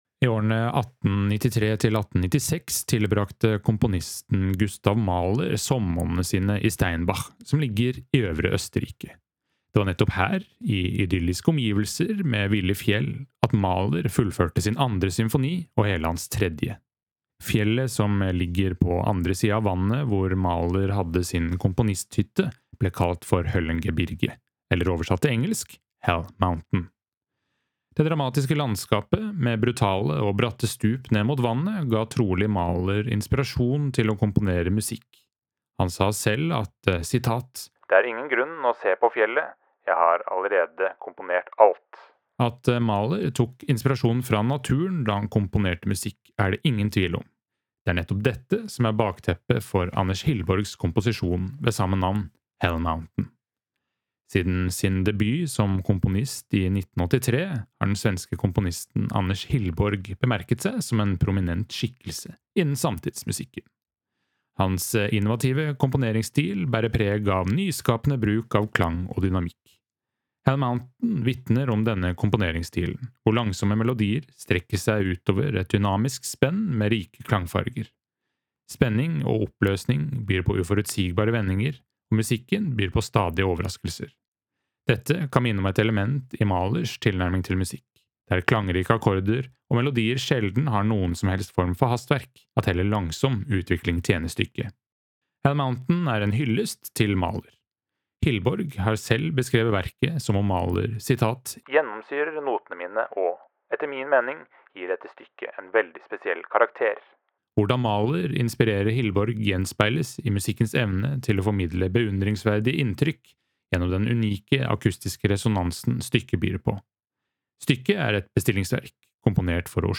VERKOMTALE-Anders-Hillborgs-Hell-Mountain.mp3